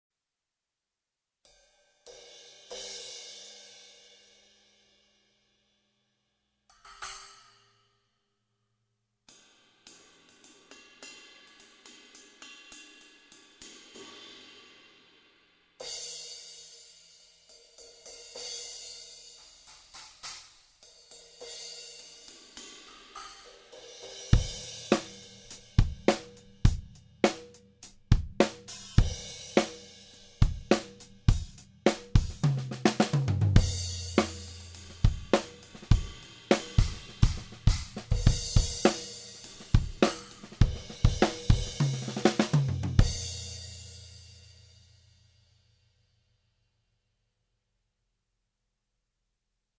Beta91A bass drum mic
Beta 98AMP tom mic
The complete review of the new Shure Beta 91A bass drum mic, Beta 181 side-address condenser, and Beta 98AMP tom mic is on page 32 of the September issue of Modern Drummer magazine.
Shure-mics.mp3